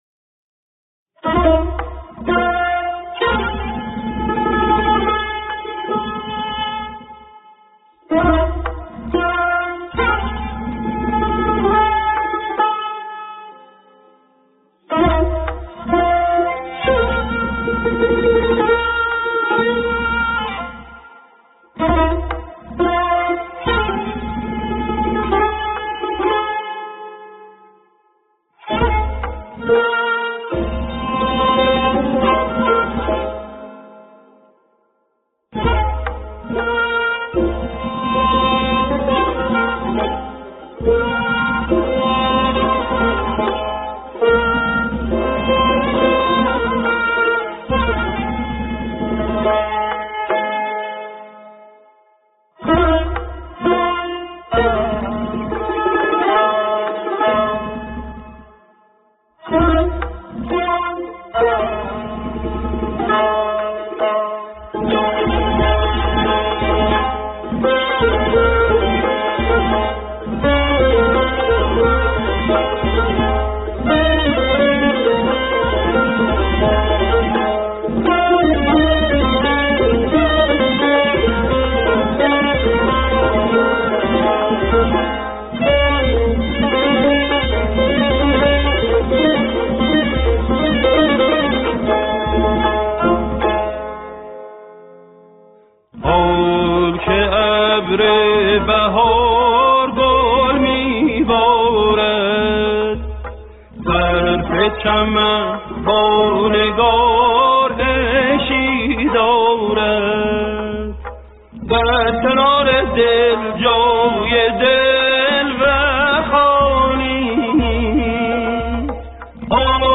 در مقام سه گاه